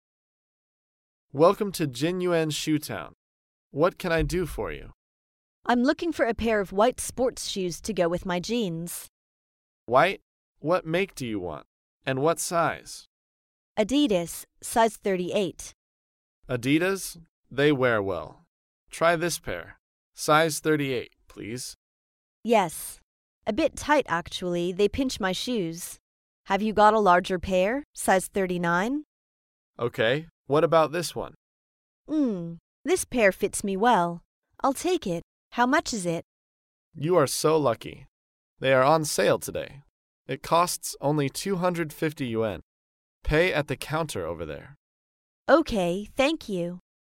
在线英语听力室高频英语口语对话 第289期:购买运动鞋的听力文件下载,《高频英语口语对话》栏目包含了日常生活中经常使用的英语情景对话，是学习英语口语，能够帮助英语爱好者在听英语对话的过程中，积累英语口语习语知识，提高英语听说水平，并通过栏目中的中英文字幕和音频MP3文件，提高英语语感。